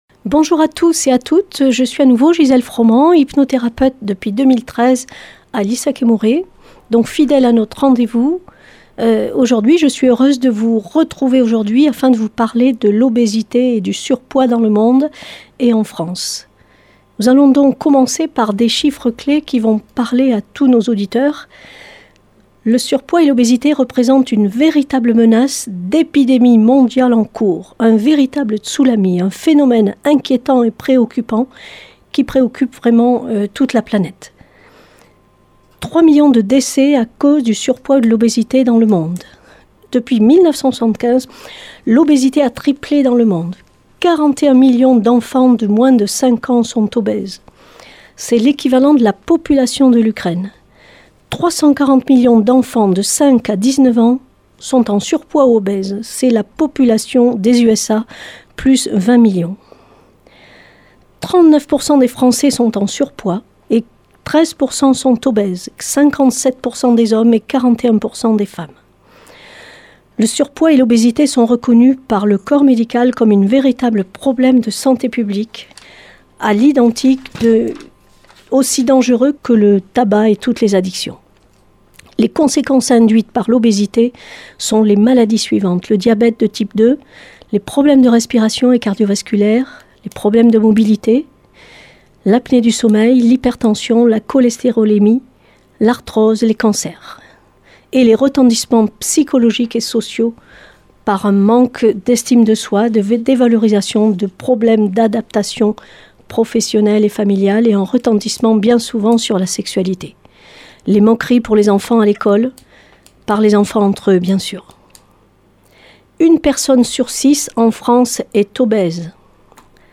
Aujourd’hui elle finit le cycle d’émissions sur le Poids et l’Obésité. Nous écouterons à la fin de l’émission 1 personne qui a fait appel à elle